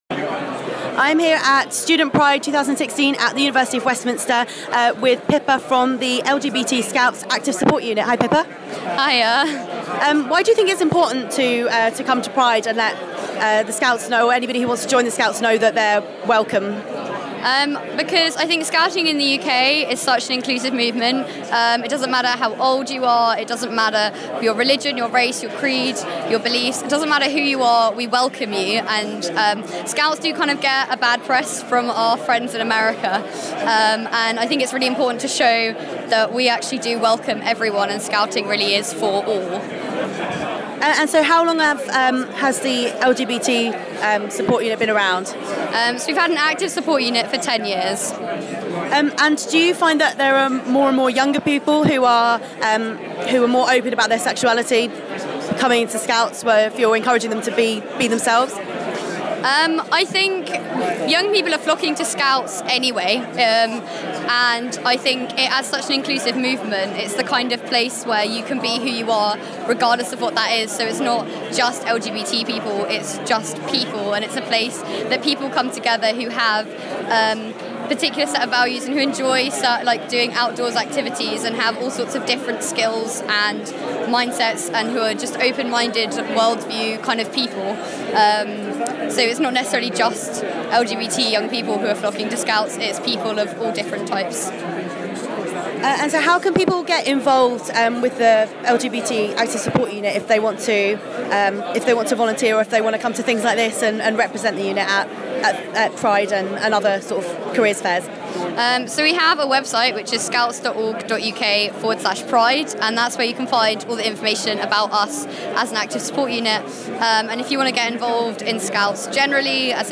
Scouts Interview